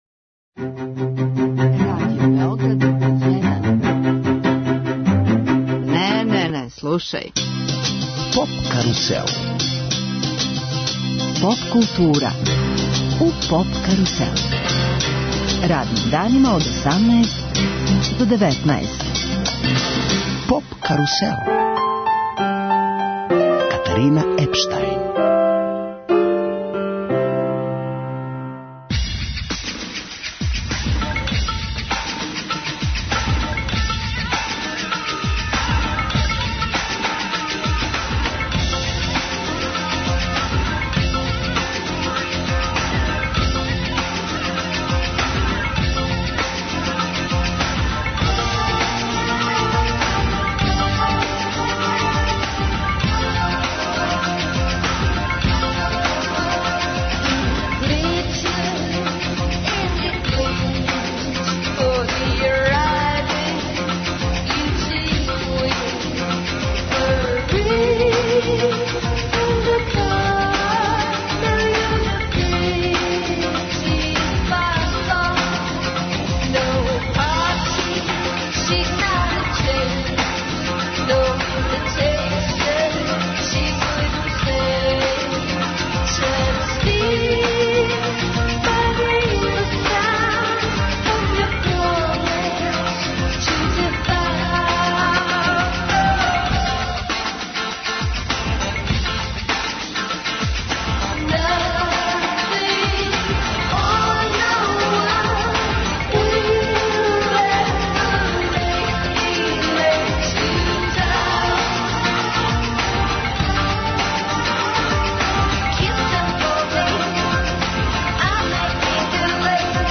Гoсти eмисиje су члaнoви сaстaвa Aртaн Лили, пoвoдoм кoнцeртa у Mиксeру, 25. нoвeмбрa.